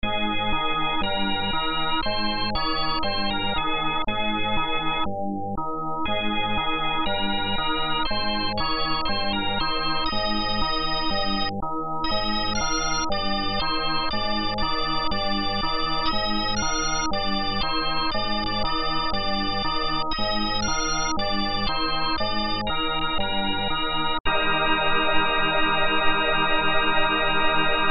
Ein auf alt getrimmtes Lied.
Piano
Selten harmonierten eine ausgesprochen ausgefallene Rhythmik mit derart schönen Melodien.
Der Hörer erlebt ein beinahe unausschöpfliches Spektrum an Klängen, in dem Moderne und Klassik drahtlos in einander überfliessen.